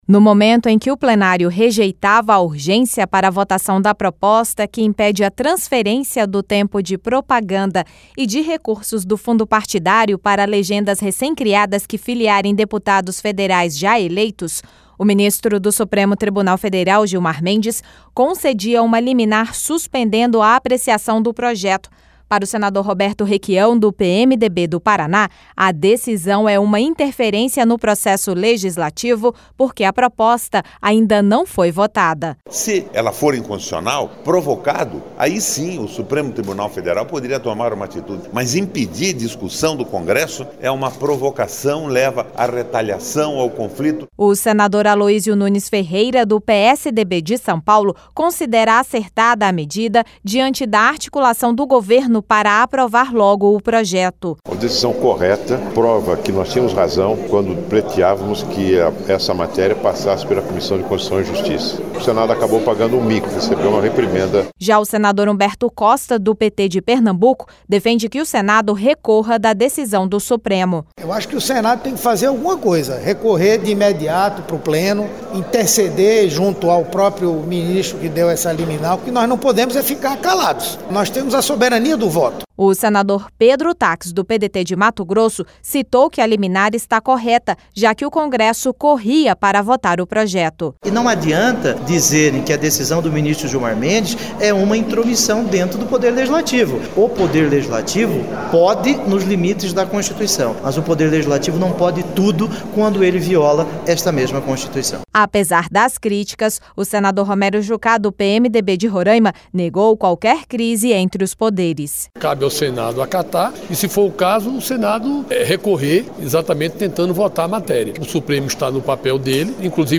(Repórter) No momento em que o Plenário rejeitava a urgência para votação da proposta que impede a transferência de tempo de propaganda e de recursos do Fundo Partidário para legendas recém-criadas que filiarem deputados federais já eleitos, o ministro do Supremo Tribunal Federal, Gilmar Mendes, concedia uma liminar suspendendo a apreciação do projeto.